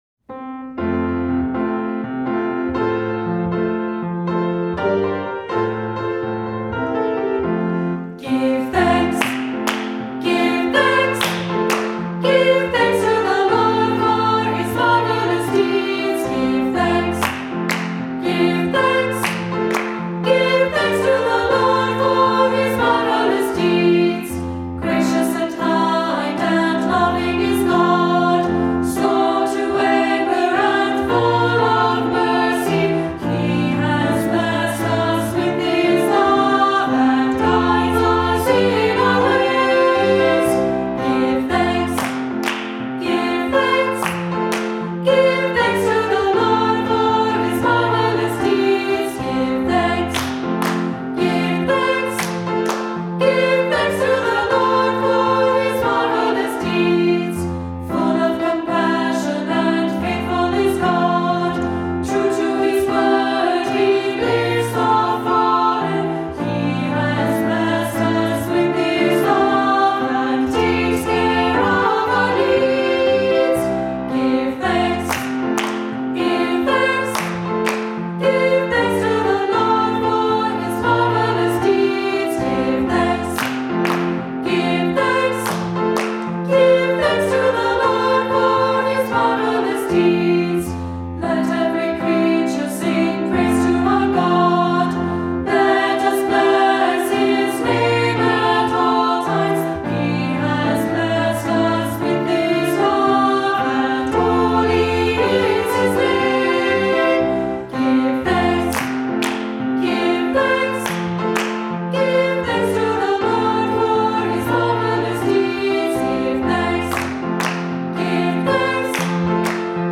Voicing: Two-part Children's Choir - SA